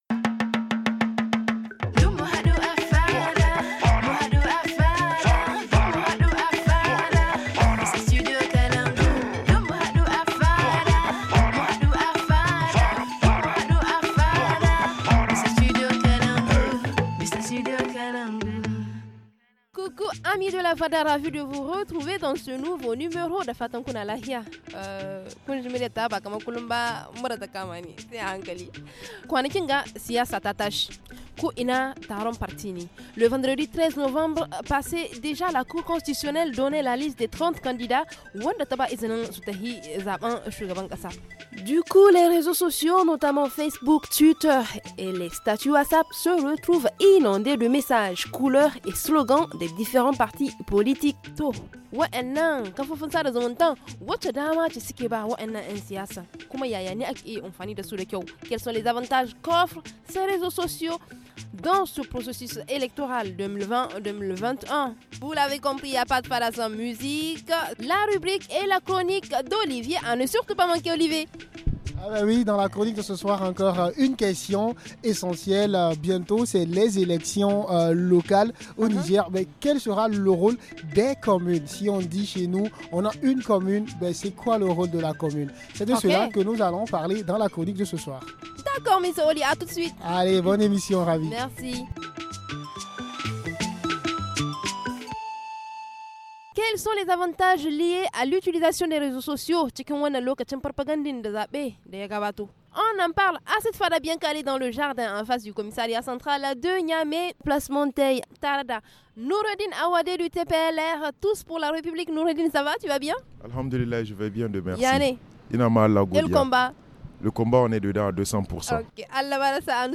Depuis la place Monteil en face du commissariat central de Niamey on en parle avec :